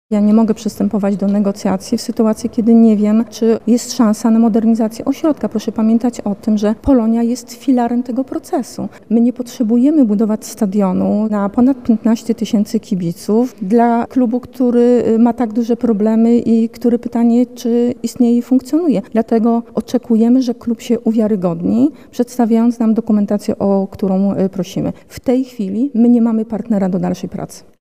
Jednak obecnie wszelkie działania zostały zawieszone – dodaje Renata Kaznowska.